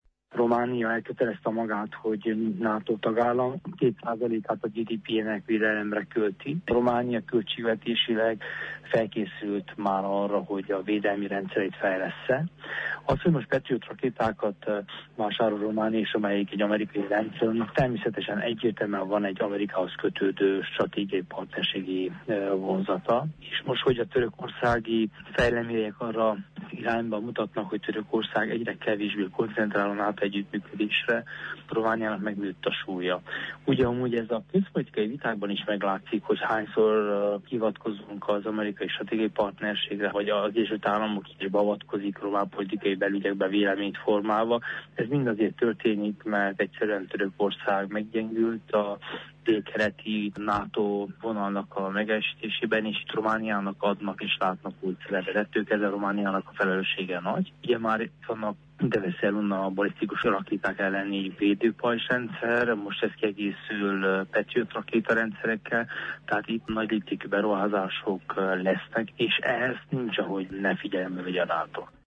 Korodi Attila képviselő, a külügzi biyottség tagja nyilatkozott rádiónknak.